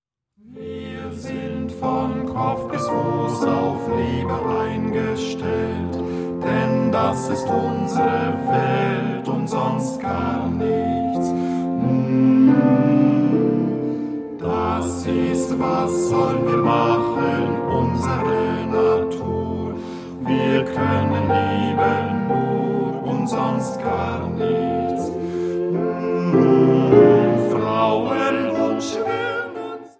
einen blauen Kristallglasflügel der Firma Schimmel